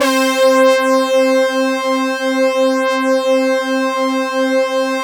BRASS2 MAT.2.wav